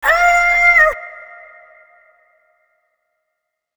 TS Vox_9.wav